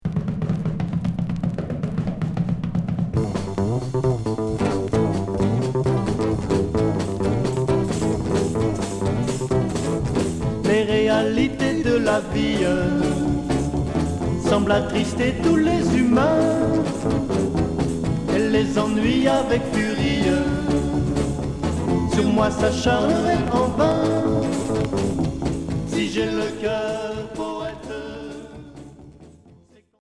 Pop rock garage religieux